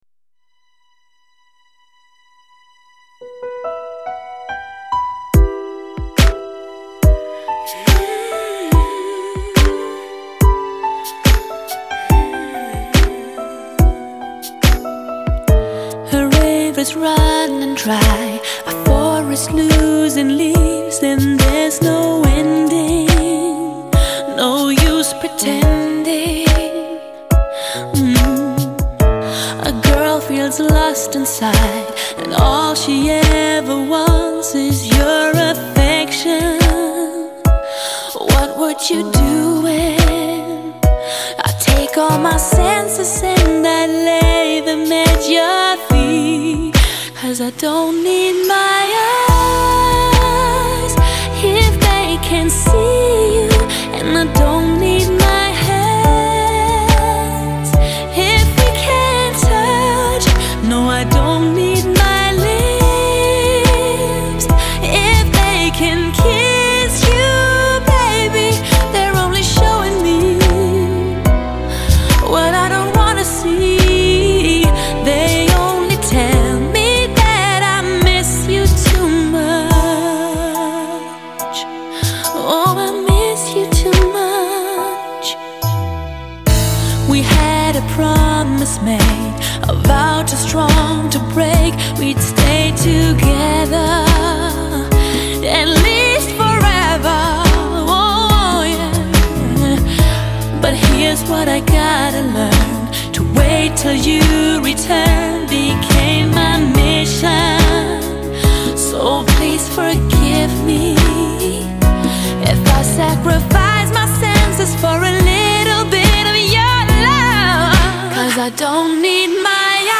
[欧美流行]
精选18首绝品抒情音乐，每天聆听了心情